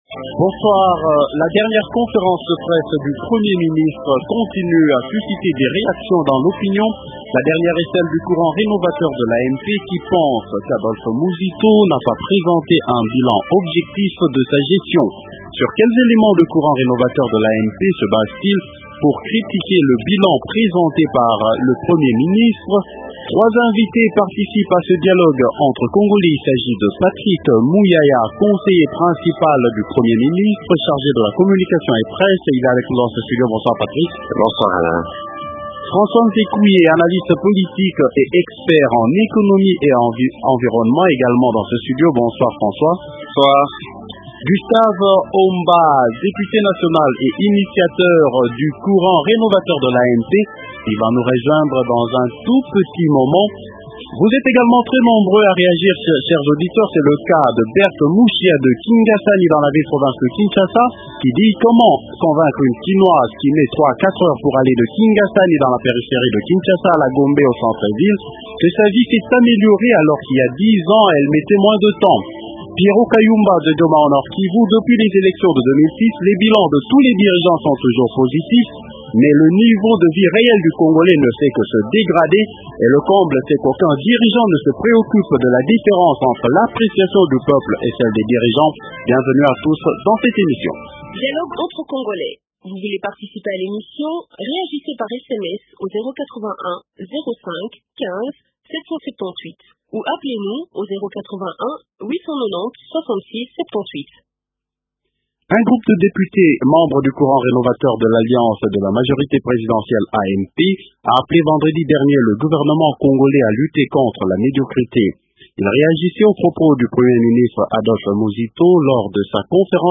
- Sur quels éléments le courant rénovateur de l’AMP se base-t-il pour critiquer le bilan présenté par le premier ministre ? Invité -Patrick Muyaya, Conseiller principal du 1er Ministre chargé de la communication et presse. -Gustave Omba, Député national et initiateur du courant rénovateur de l’Amp.